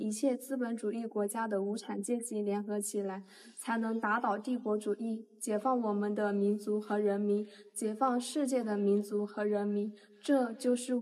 心地よく優しい女性ナレーションボイス
オーディオブック、瞑想、ドキュメンタリーに安らぎと明瞭さをもたらすために設計された、穏やかで表現力豊か、そして優しい女性のナレーションボイスを体験してください。
テキスト読み上げ
心地よいトーン
優しいナレーション